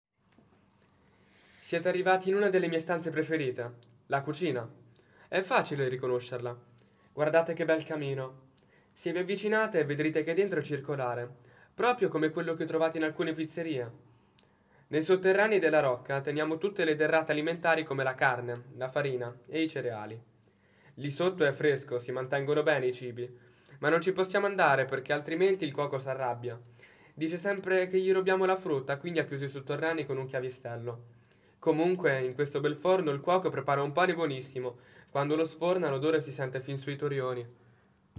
Audioguida 0-12
Ascolta Riccardino, il cavaliere della Rocca